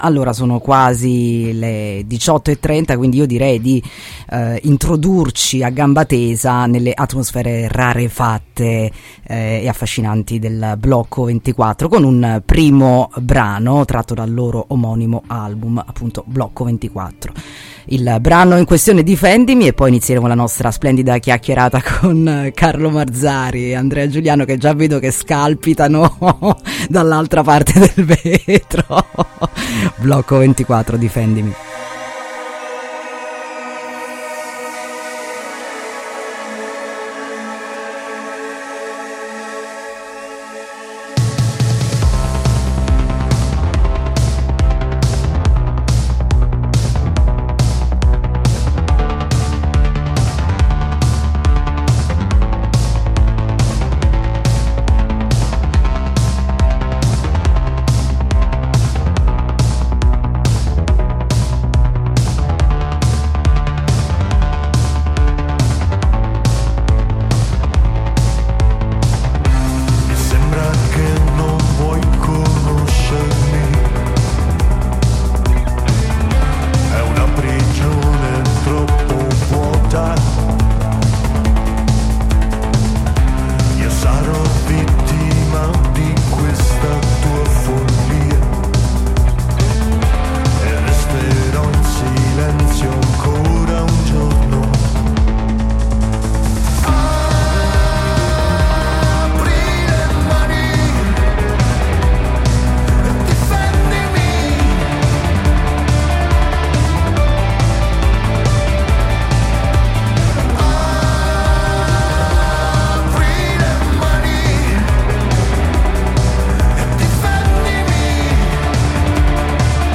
INTERVISTA BLOCCO24 ( Onda Sonora) | Radio Città Aperta